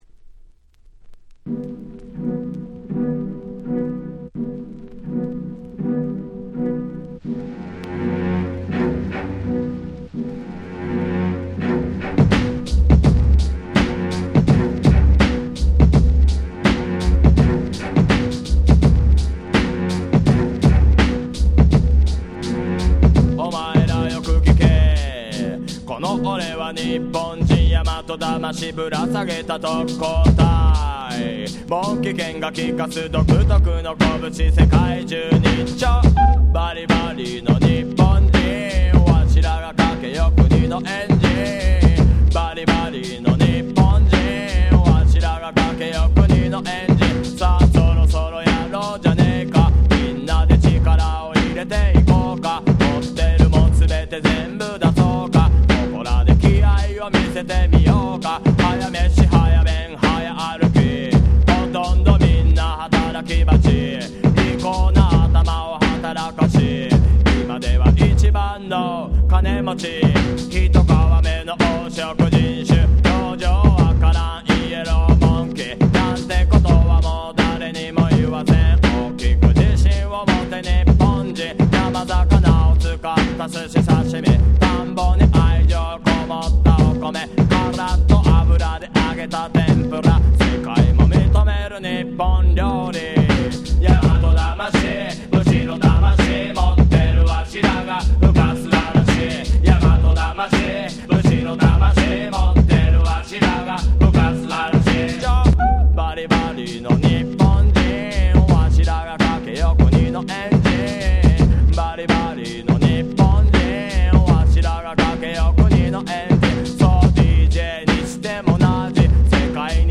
98' Very Nice Japanese Hip Hop / Reggae !!
レゲエ ジャパレゲ 日本語Rap　J-Rap 90's